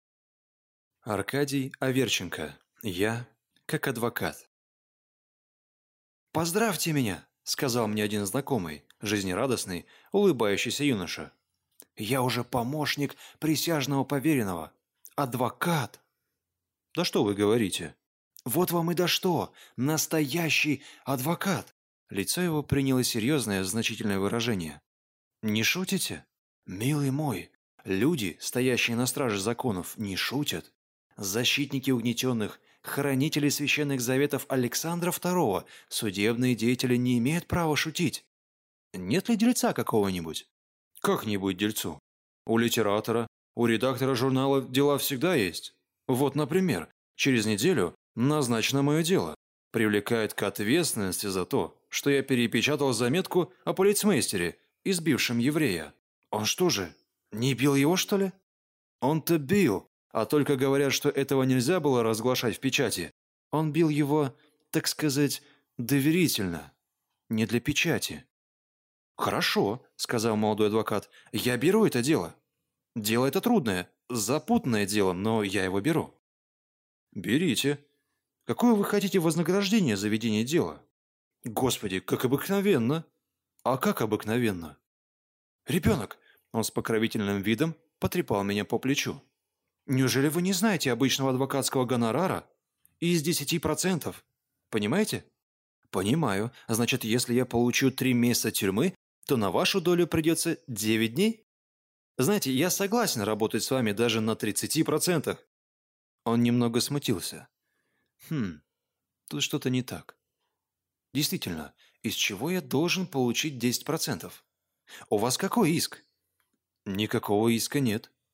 Аудиокнига Я – как адвокат | Библиотека аудиокниг